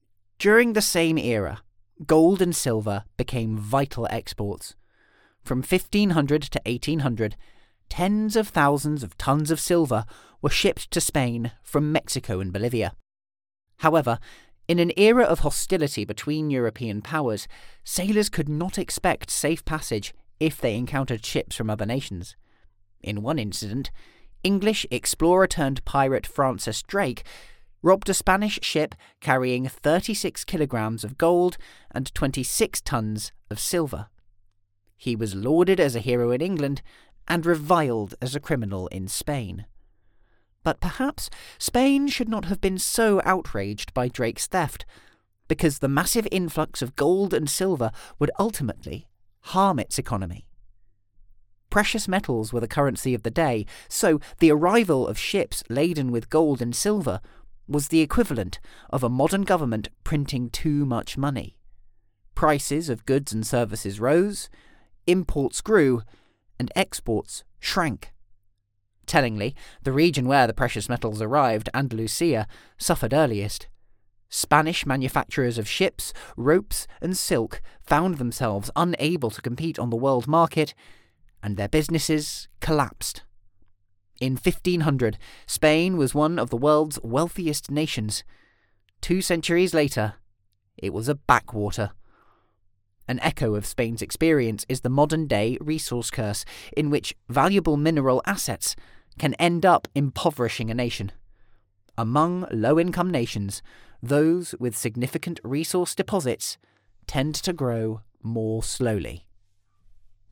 Non-Fiction Audiobook Sample
Male
Neutral British
Youthful
non-fiction-audiobook-sample.mp3